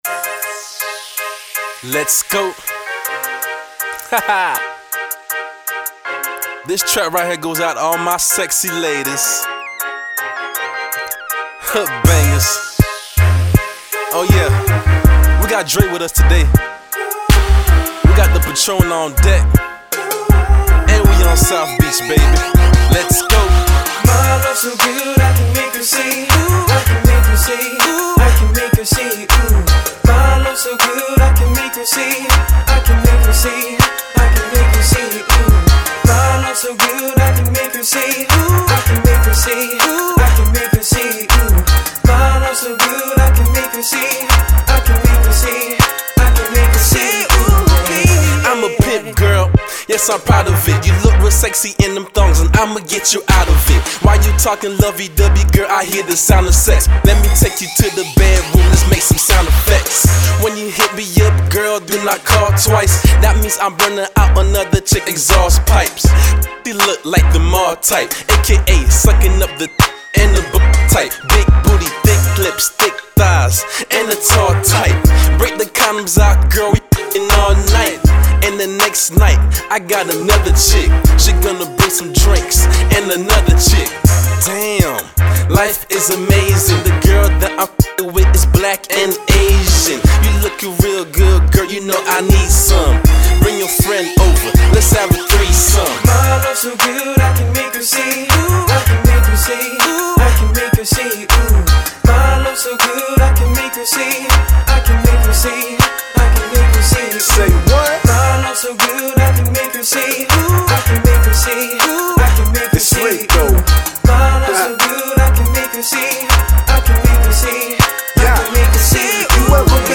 hiphop
the Ft. Lauderdale based hip hop team